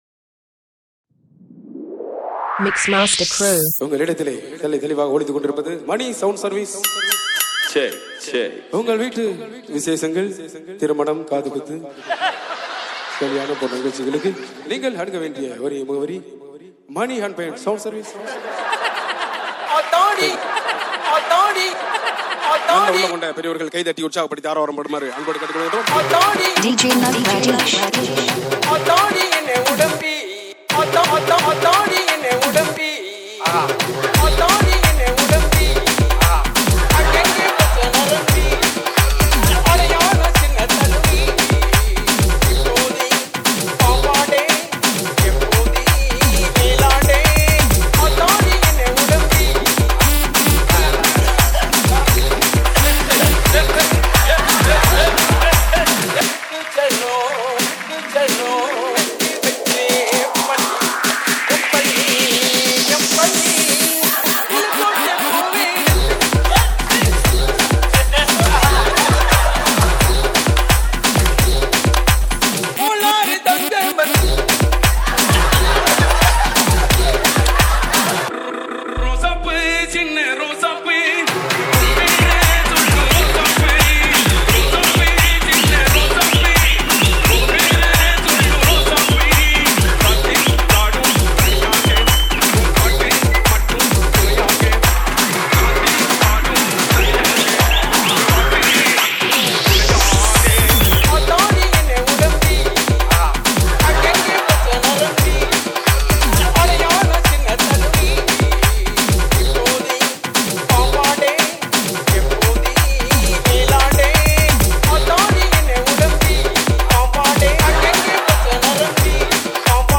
ALL TAMIL ROMANTIC DJ REMIX